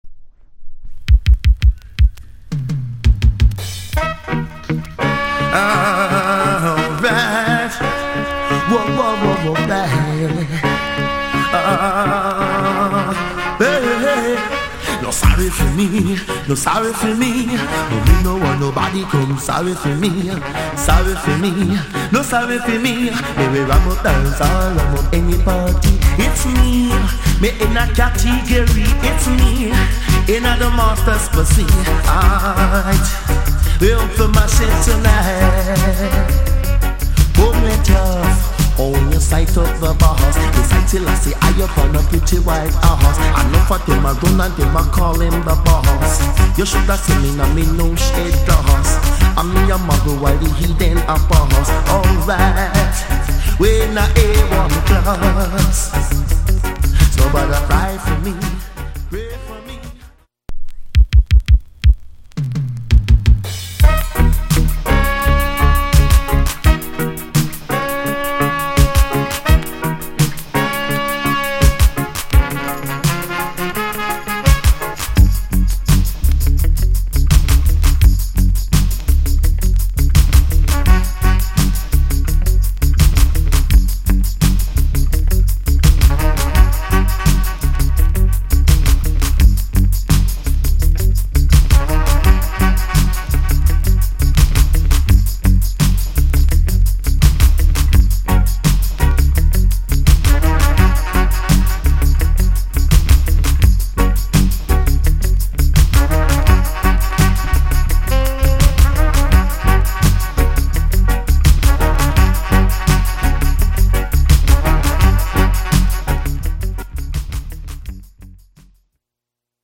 * Late 80's Good Dancehall Singer